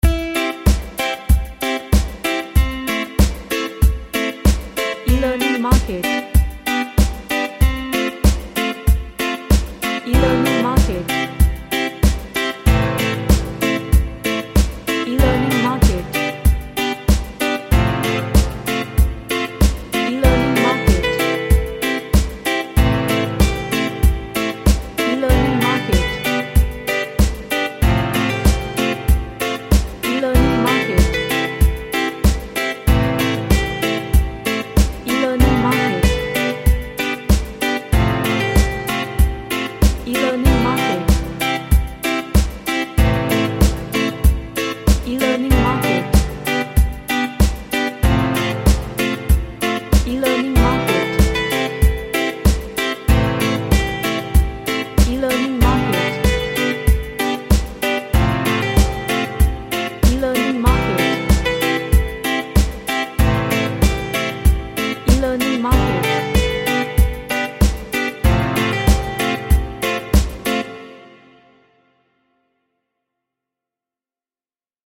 A Happy reggae track with lots of drums.
Happy